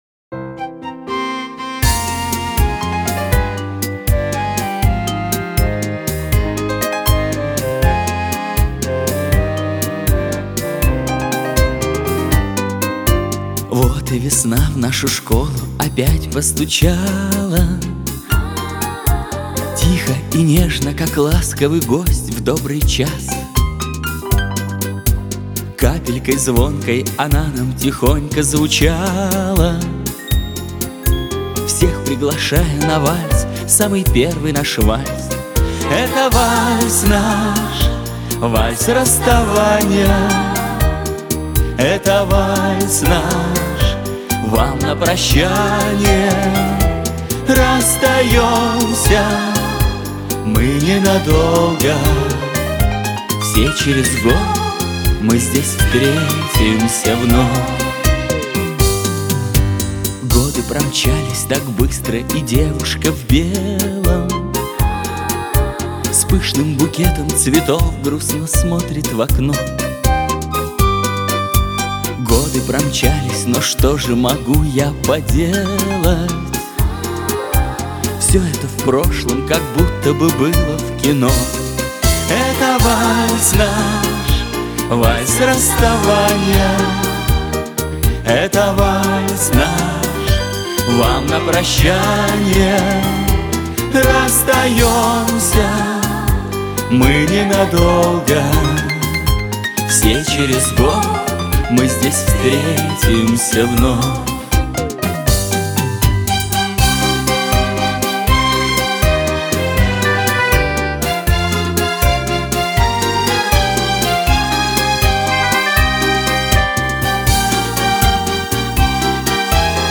• Категория: Детские песни
выпускной вальс